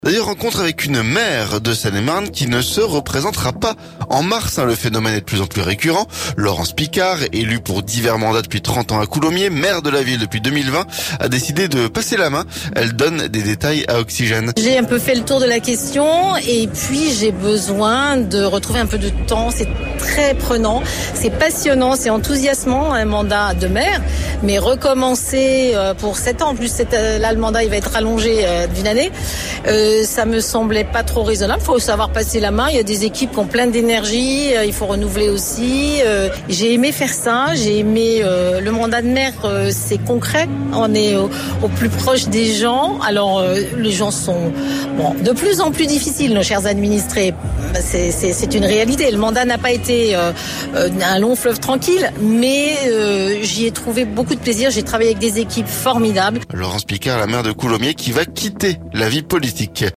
En effet, après 30 ans de mandats locaux, dont 6 comme maire, elle décide de ne pas se représenter aux élections de mars. Et explique les raisons de ce choix au micro Oxygène.